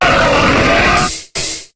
Cri d'Hippodocus dans Pokémon HOME.